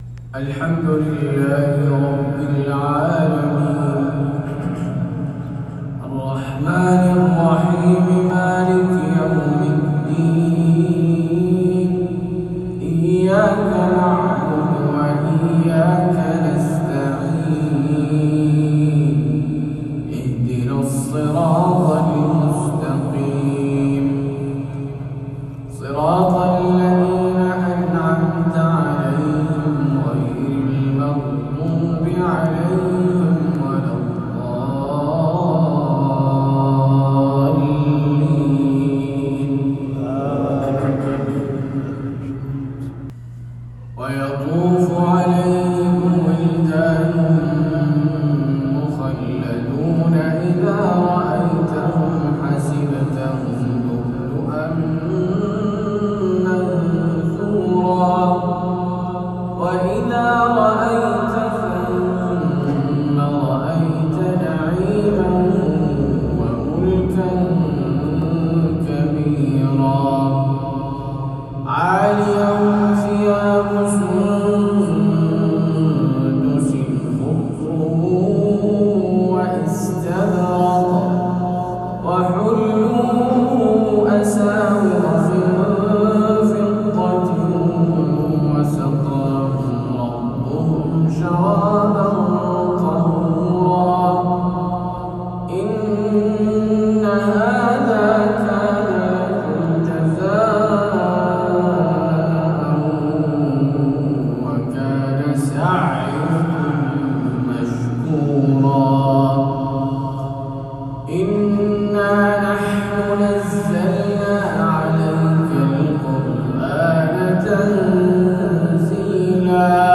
عشائية
بمسجد حسن الشاعر، بحي الظاهرة بالمدينة النّبوية.